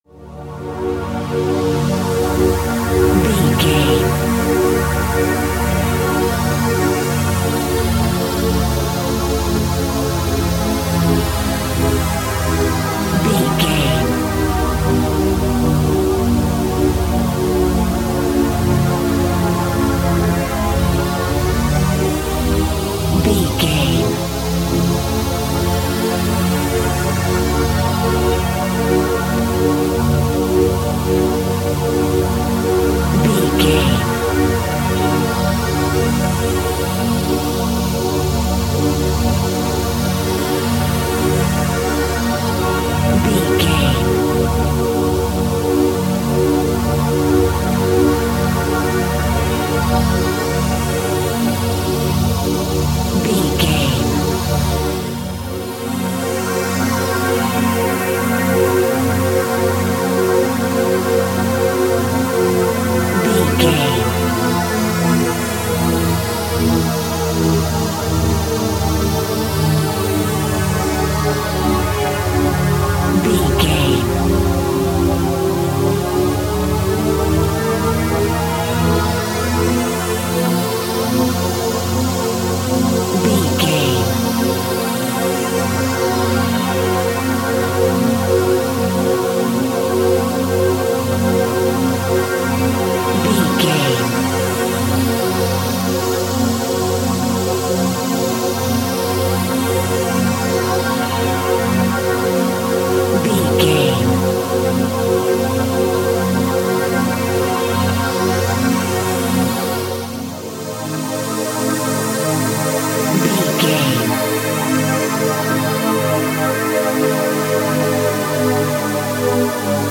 In-crescendo
Thriller
Atonal
scary
ominous
dark
eerie
synthesizer
mysterious
horror music
Horror Pads
horror piano
Horror Synths